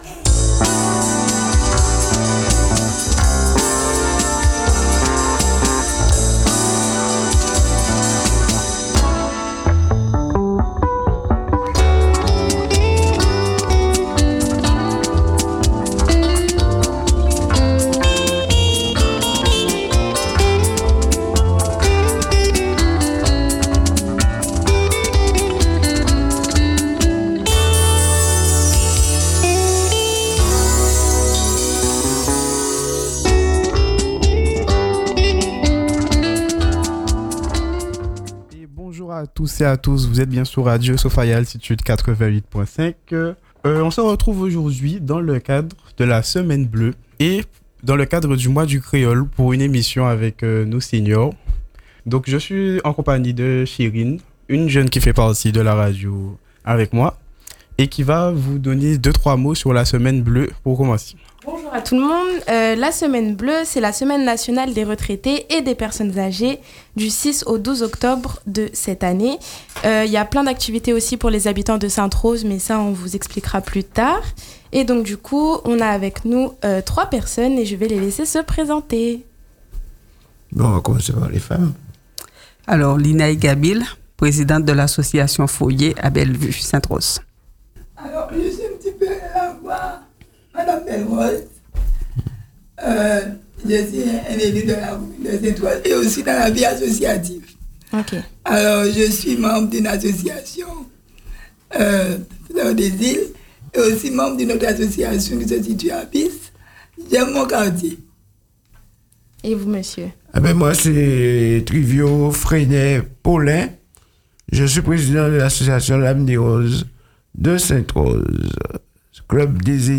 Semaine Blue : émission sur le thème « Palé Kréol », animée par les jeunes de Radio Sofaïa Altitude.